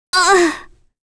Kirze-Vox_Damage_02.wav